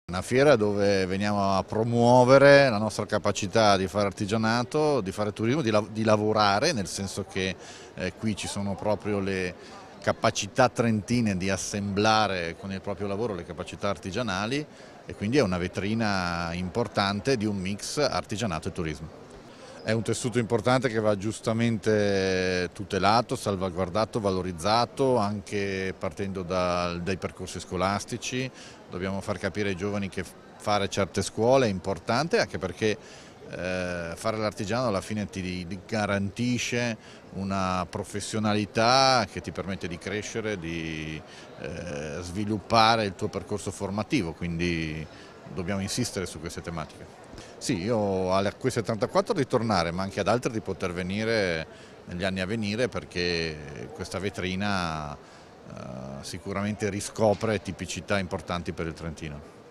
ARTIGIANO_IN_FIERA_-_INT_MAURIZIO_FUGATTI.mp3